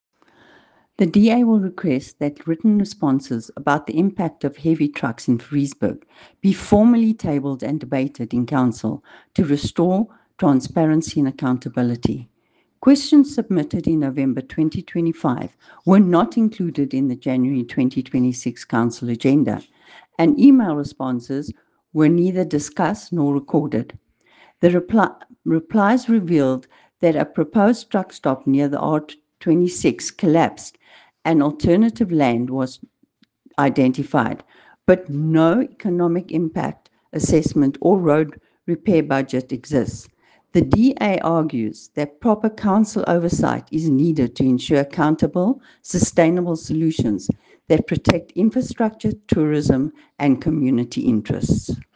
Afrikaans soundbites by Cllr Irene Rügheimer and Sesotho soundbite by Jafta Mokoena MPL with a response from the mayor here